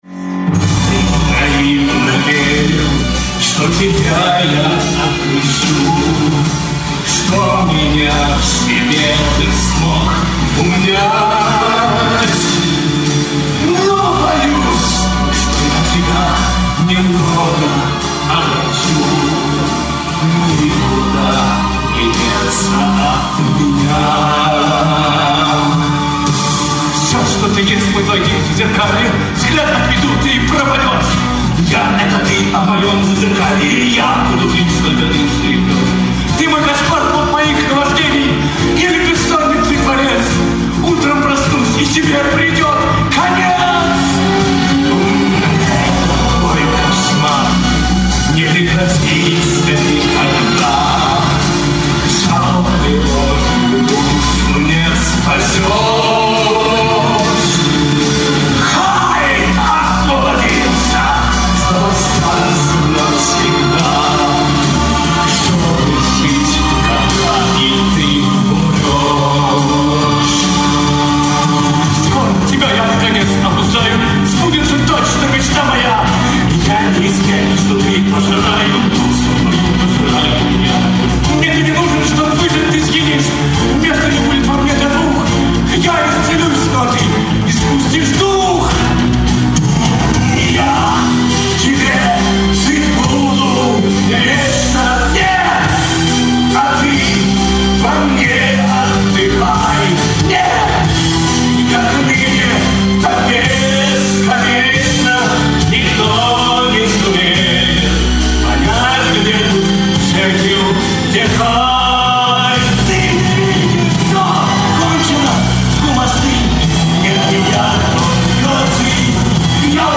Музыкальная драма в 2-х частях
Аудио запись со спектакля от 26.05.2005 г*.